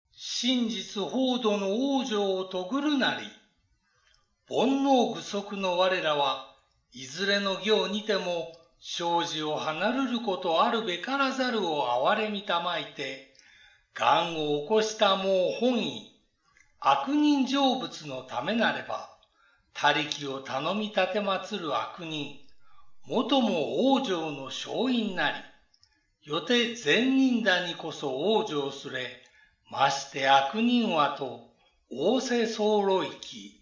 この解説サイトは電子書籍にリンクが貼られていますが、電子書籍をダウンロードせずに読まれる方（主にスマートフォンで読まれる方）のために、電子書籍の表紙とページの画像、語句解説、朗読音声などが含まれています。